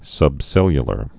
(sŭb-sĕlyə-lər)